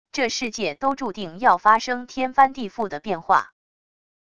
这世界都注定要发生天翻地覆的变化wav音频生成系统WAV Audio Player